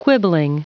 Prononciation du mot quibbling en anglais (fichier audio)
Prononciation du mot : quibbling